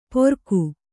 ♪ porku